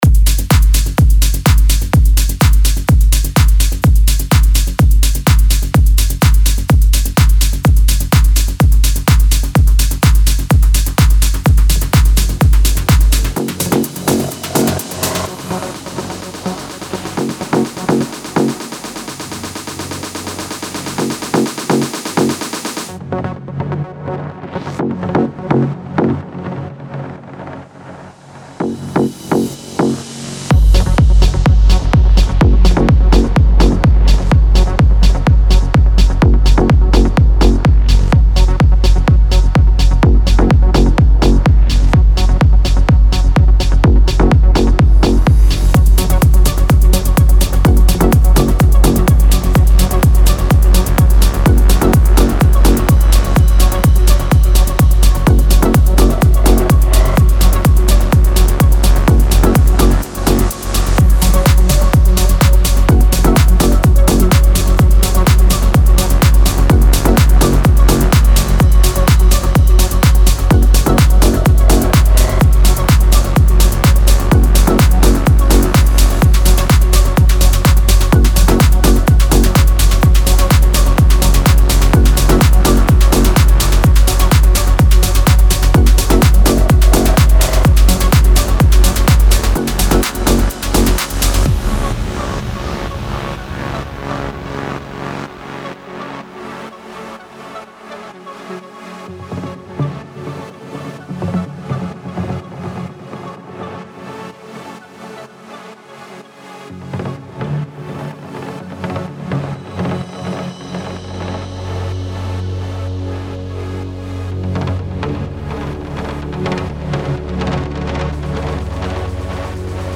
Melodic Techno Techno Trance